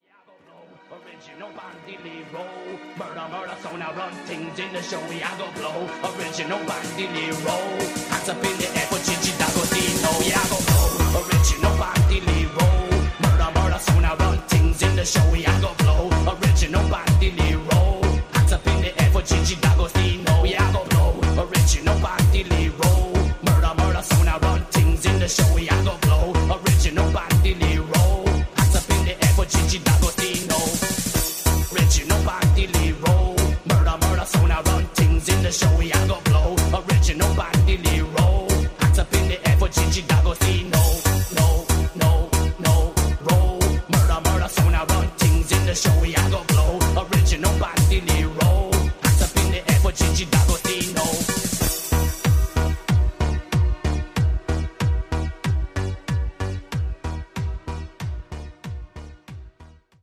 poi sempre dal Live @ Motor Show di Bologna ...